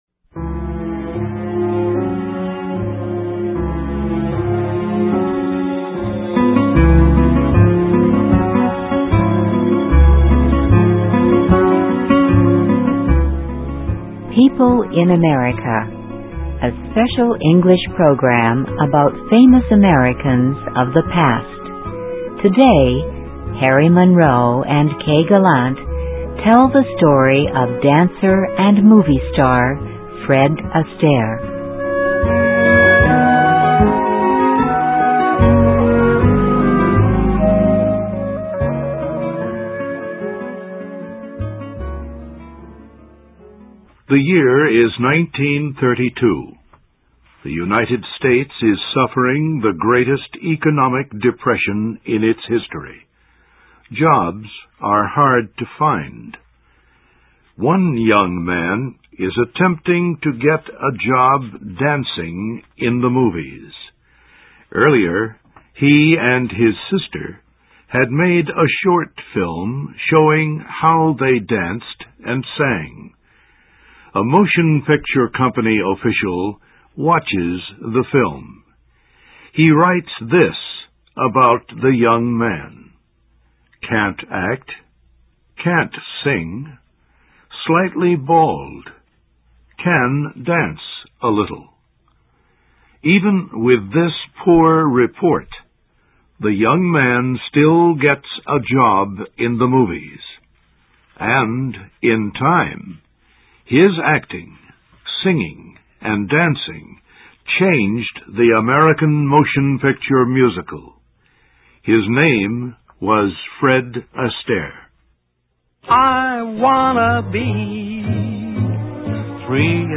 Listen and Read Along - Text with Audio - For ESL Students - For Learning English
PEOPLE IN AMERICA -- a VOA Special English program about famous Americans of the past.